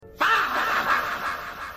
fahhhhhhhhhhh 7 Meme Sound Effect
Category: Reactions Soundboard